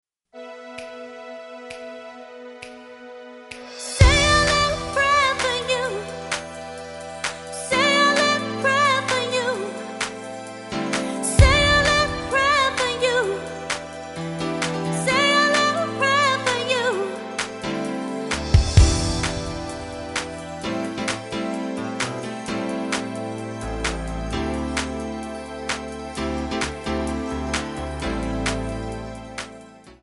Backing track files: Disco (180)